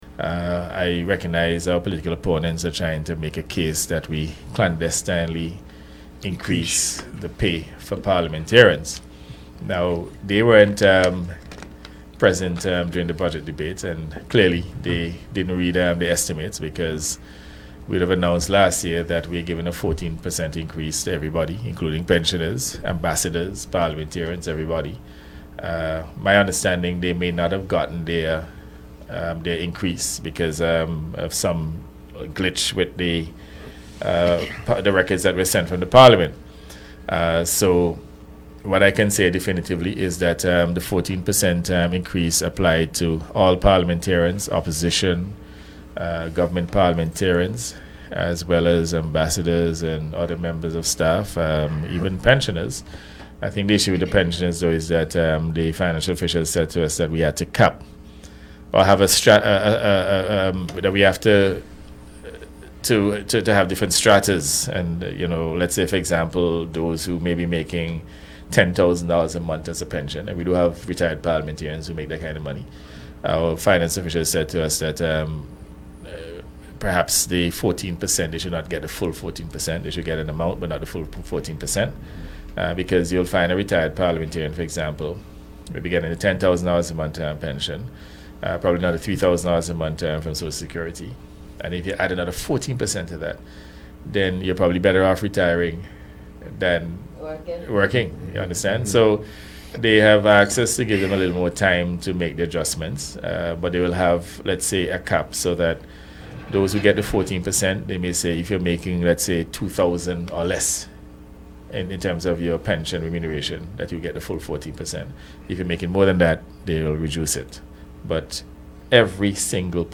Take a listen to the PM’s remarks
PM-SPEAKS-ON-SALARY-INCREASES.mp3